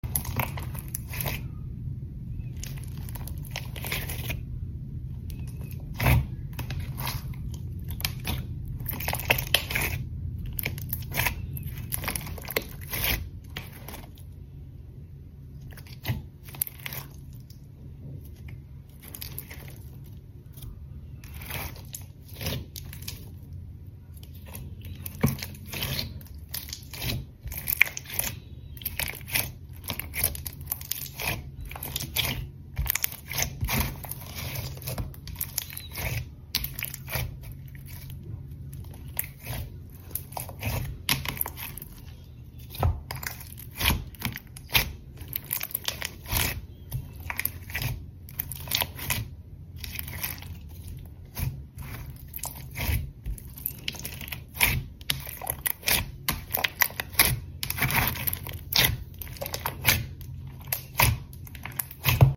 Recreating AI ASMR: How AI Sound Effects Free Download